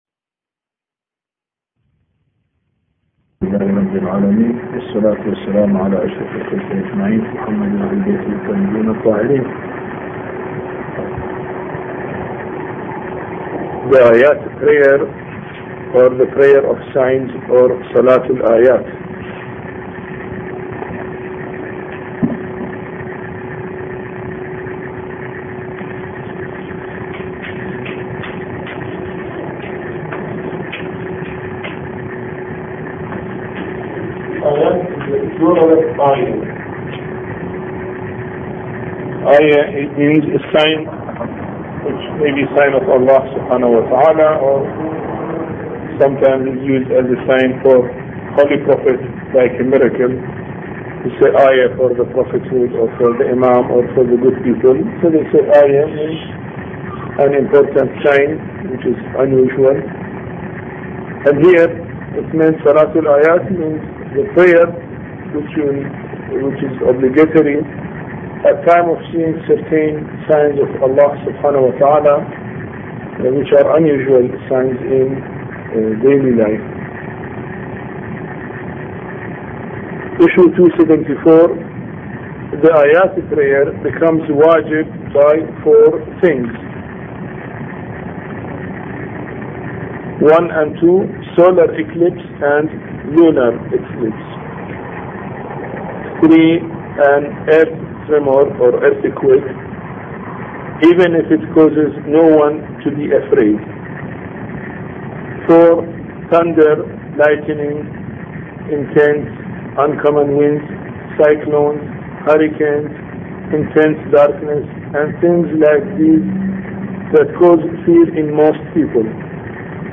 A Course on Fiqh Lecture 14